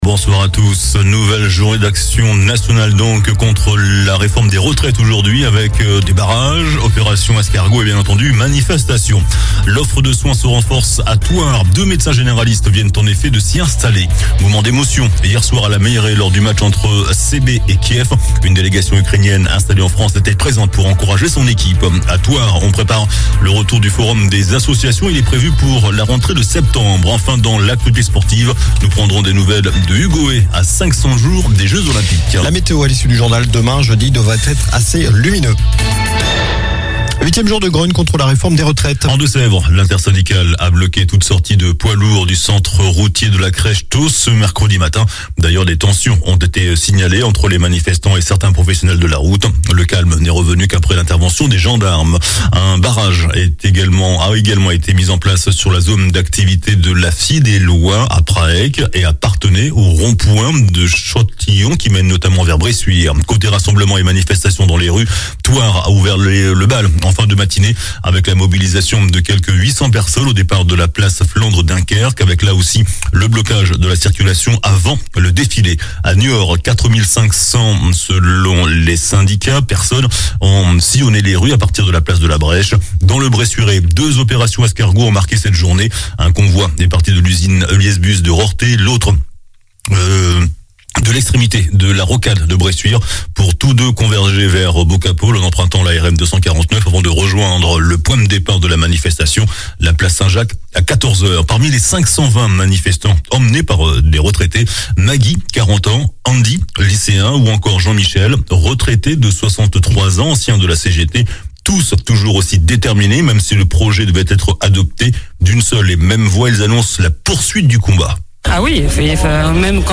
JOURNAL DU MERCREDI 15 MARS ( SOIR )